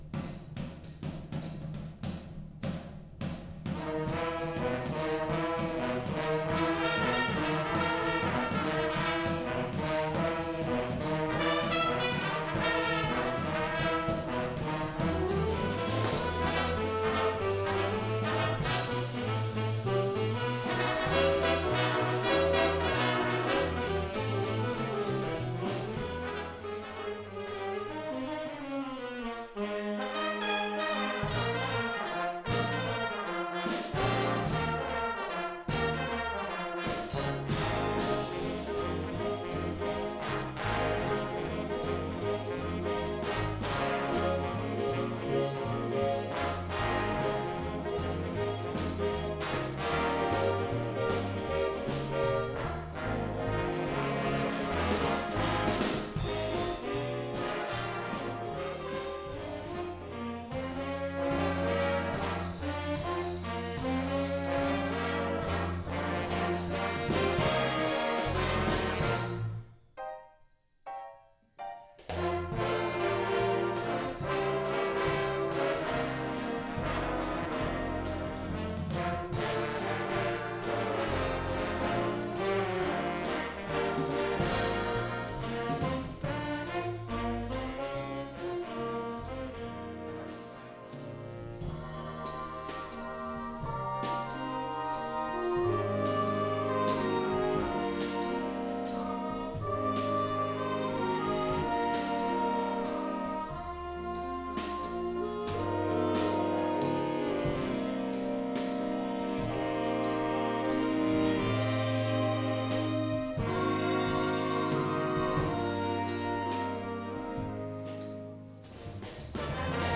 Listen to this sampler of our music!